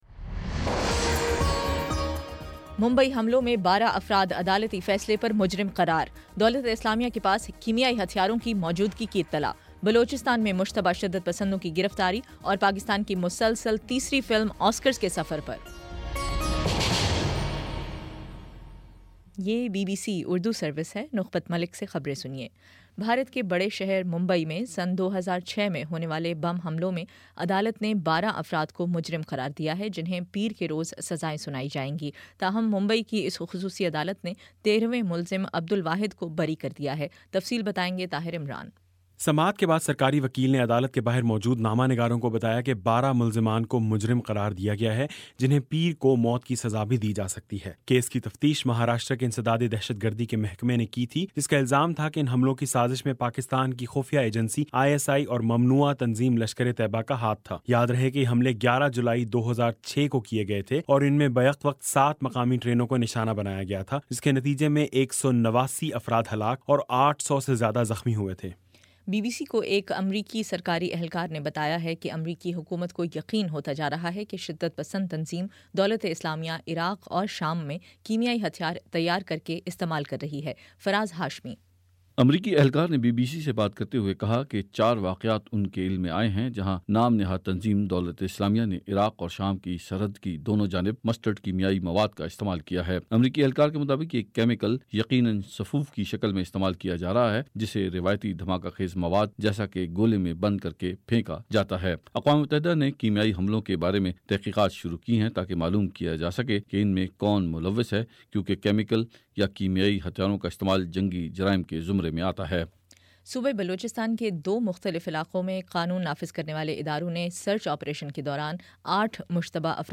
ستمبر11 : شام پانچ بجے کا نیوز بُلیٹن